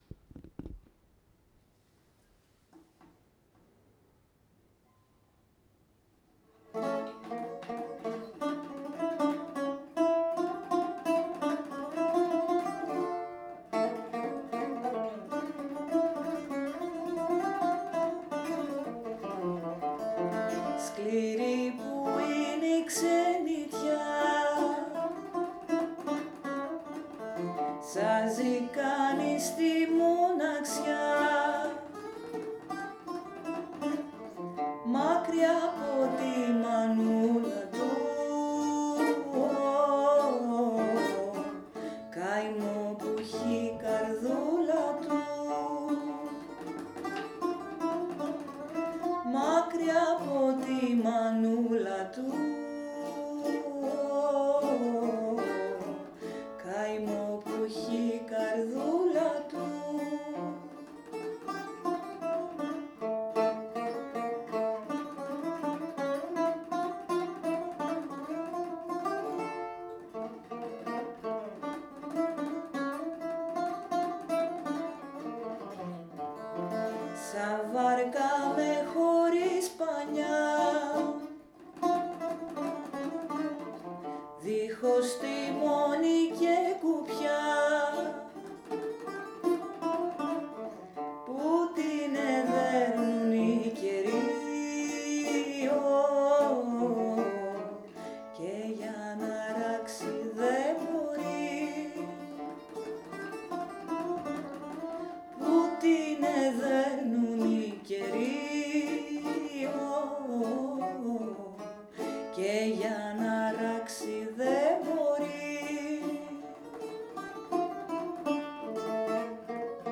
voice
buzuki